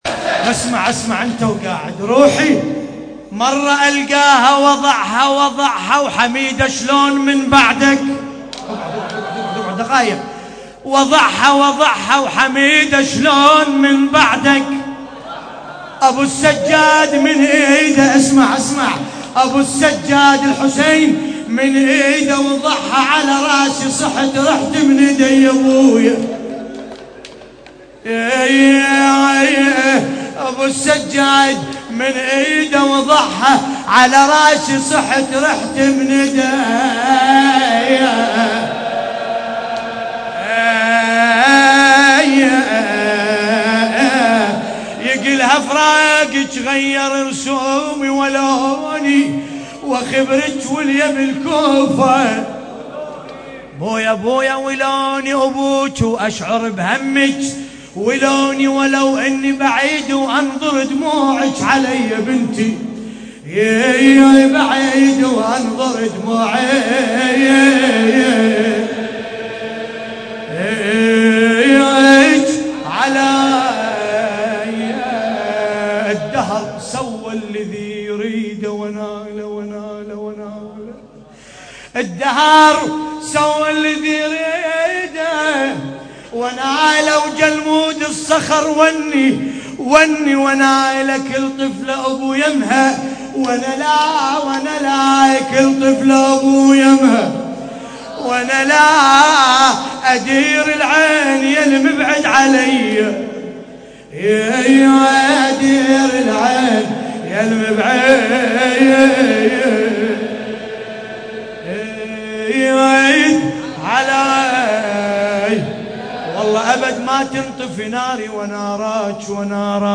نعي : حميدة شلون من بعدك وضعها
القارئ: باسم الكربلائي التاريخ: الليلة الخامسة من شهر محرم الحرام 1424 هـ - الكويت.